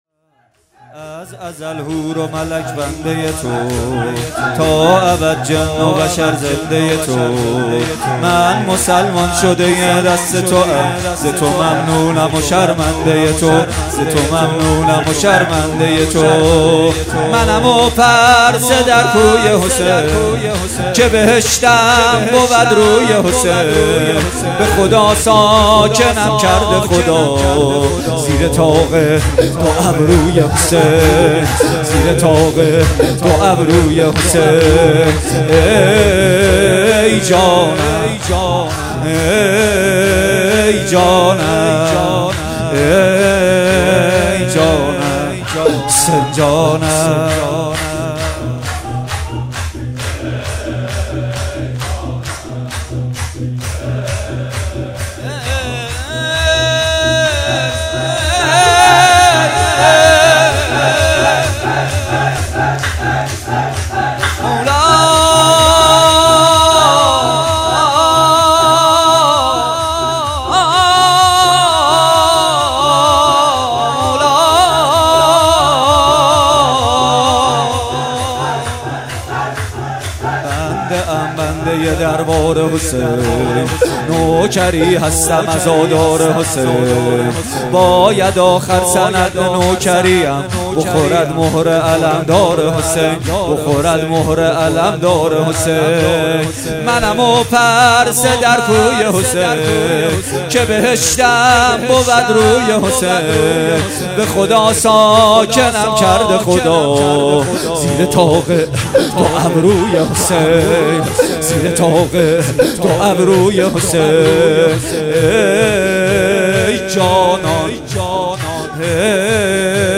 کربلایی محمد حسین پویانفر
شور محمد حسین پویانفر